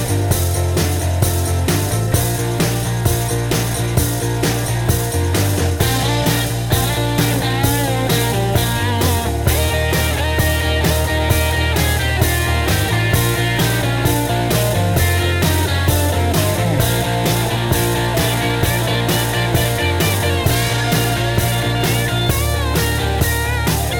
Two Semitones Down Rock 3:10 Buy £1.50